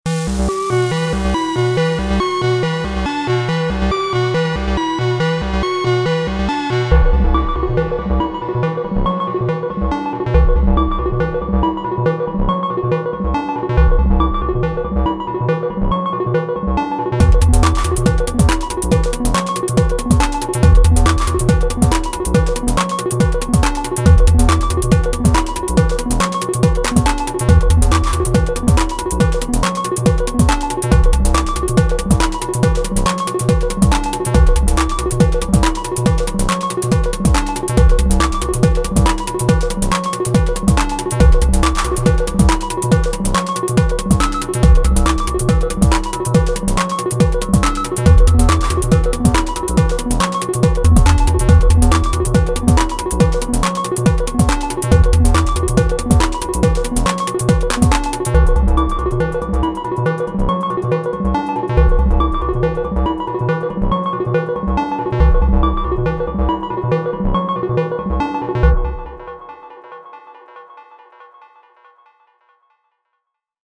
Just starting to scratch the surface of the Sy chip machine.
Sounds so lovely, nice beat too! :heart: